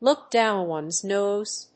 アクセントlóok dówn one's nóse